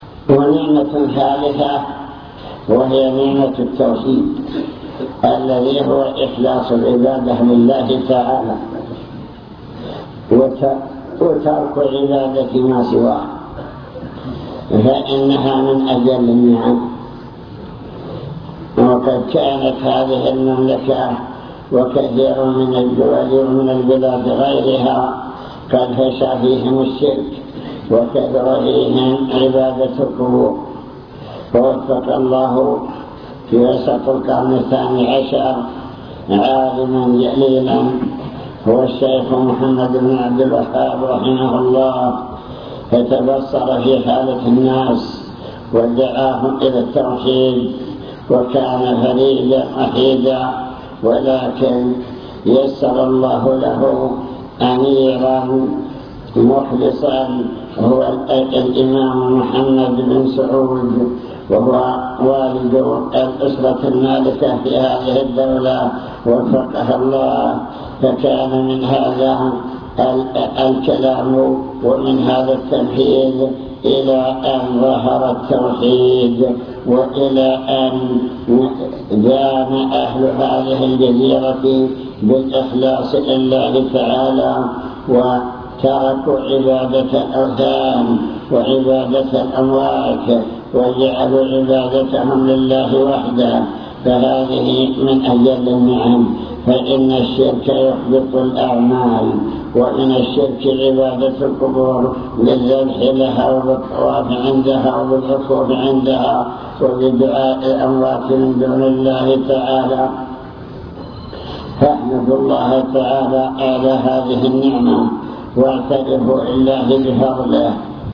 المكتبة الصوتية  تسجيلات - محاضرات ودروس  محاضرة بعنوان شكر النعم (3) نماذج من نعم الله تعالى التي خص بها أهل الجزيرة